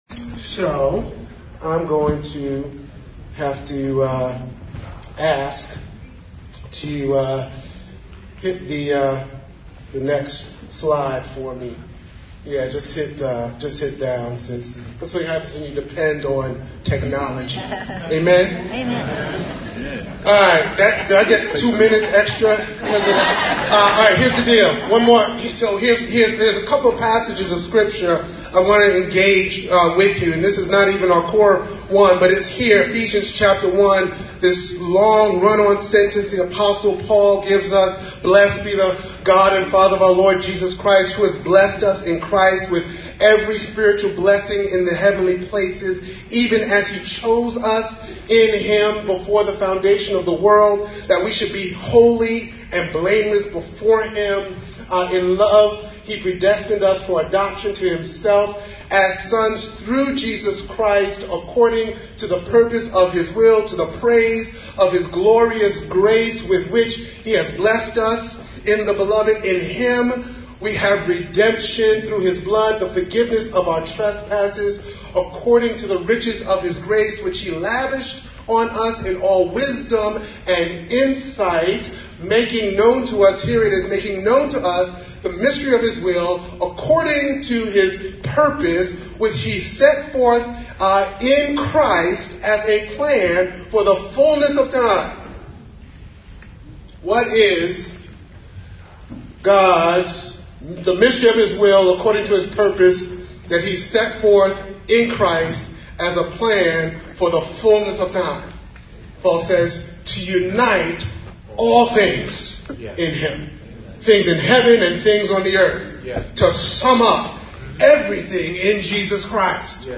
address at the 2020 Reconciliation and Justice Network Conference, held in St. Louis, Mo.